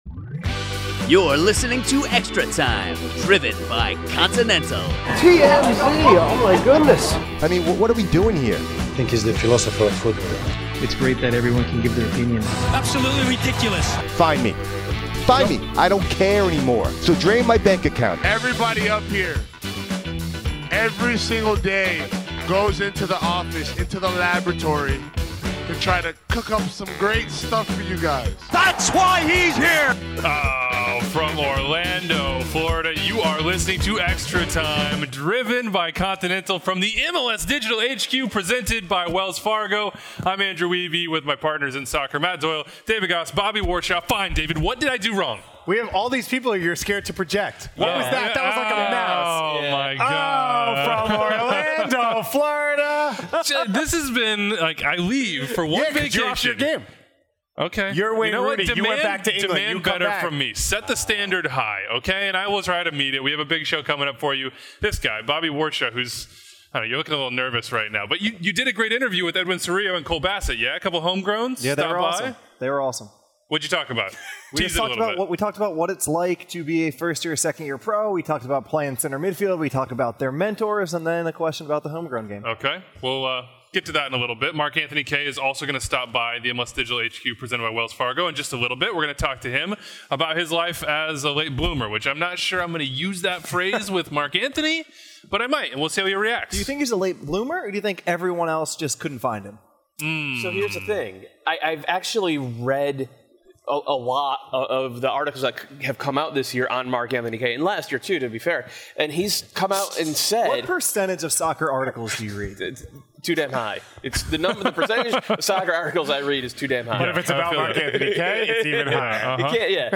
Mark-Anthony Kaye comes by the set to chat about LAFC, his Barca midfield model, why his career got off to a slow start, Canada's future and Bob Bradley in the weight room. Finally, Inter Miami's first signings cost a pretty penny.
INTERVIEW: Mark-Anthony Kaye on his late-blooming career, LAFC